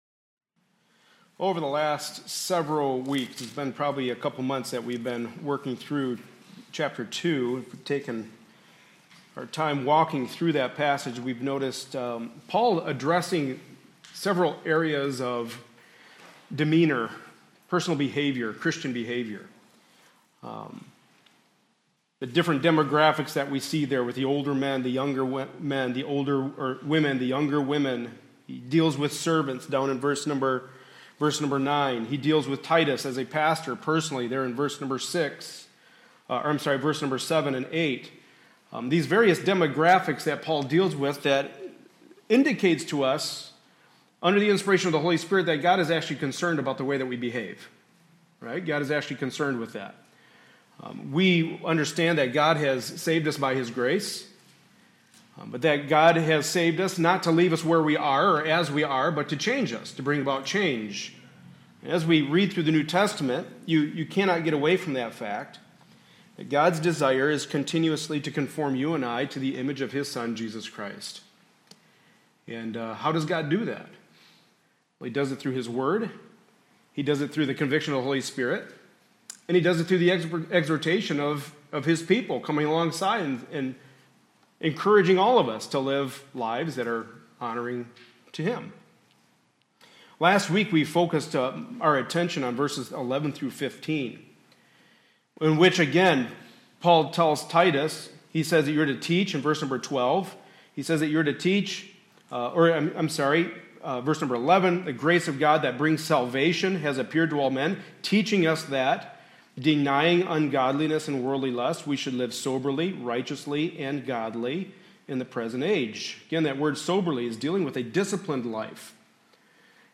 Passage: Titus 3:1-8 Service Type: Sunday Morning Service